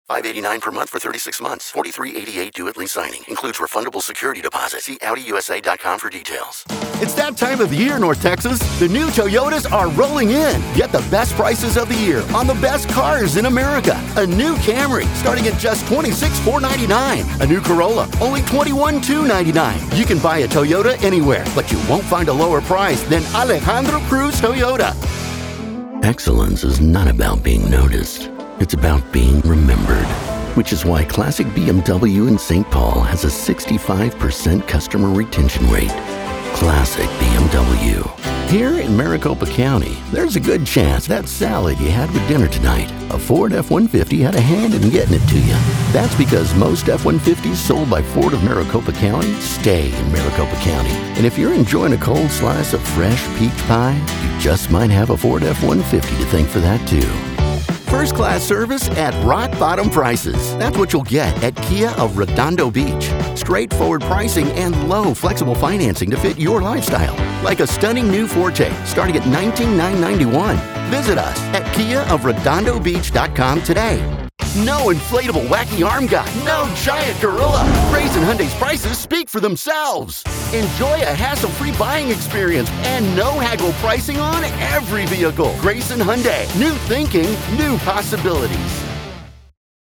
Full-Time, award-winning, bilingual voice actor with a pro studio.
Automotive Demo - English
Southern, Hispanic, Mexican, Latino, American Standard English
Middle Aged